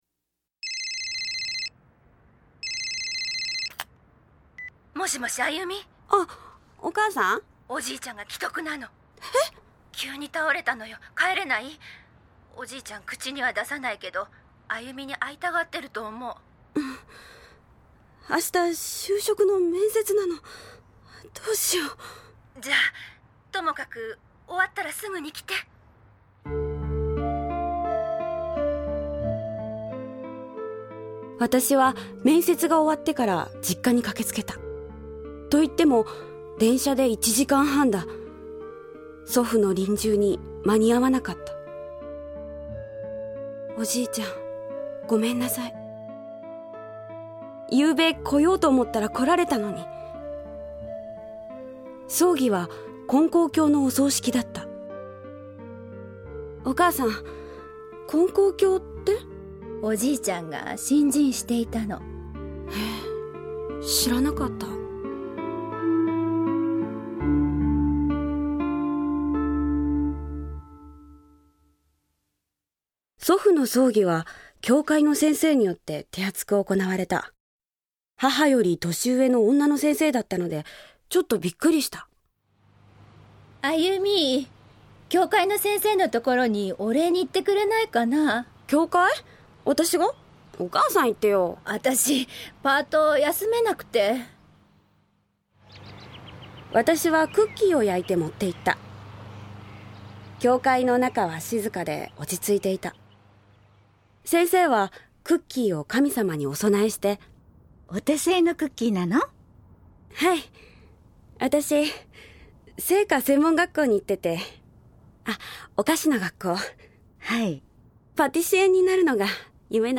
●ラジオドラマ「ようお参りです」
・教会の先生（50歳くらい・女性）